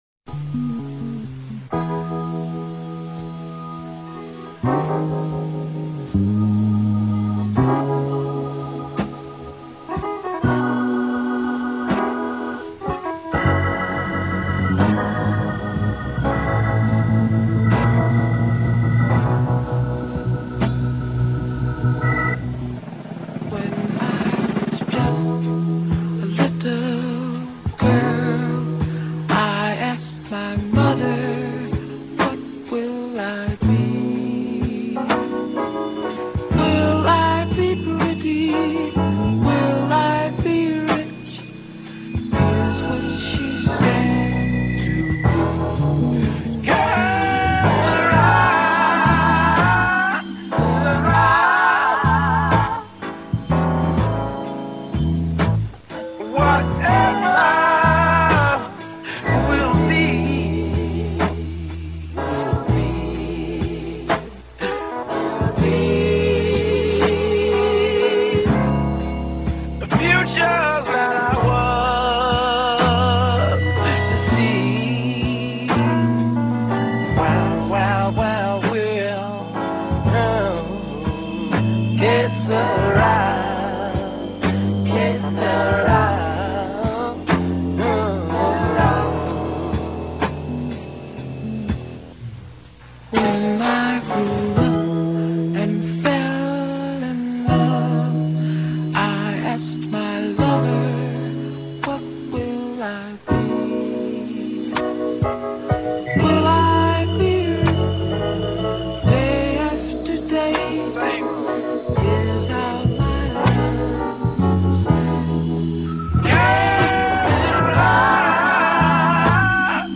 keyboards
drums
bass
guitars
percussion
horns
harmonica
background vocals